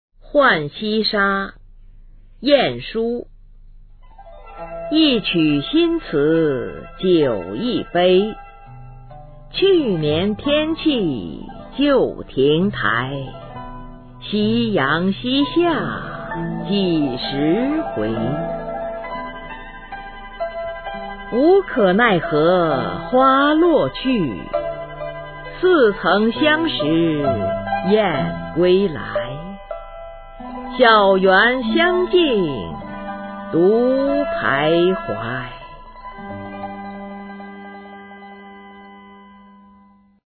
晏殊《浣溪沙》原文和译文（含赏析、朗读）